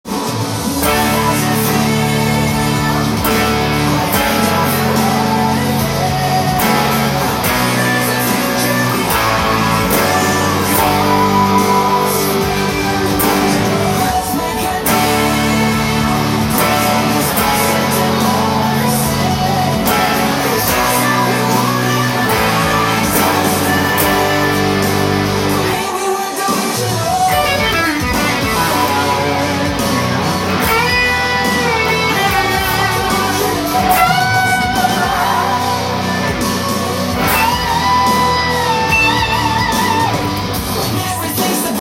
EDM系の電子音も入っていて現代的な感動できるロックナンバーです！
オリジナル音源にあわせて譜面通り弾いてみました
keyがDになるのでDのダイアトニックコードのみで
エレキギターで弾く際は、殆どパワーコードなので
サビの最後のほうにギターソロを追加していますので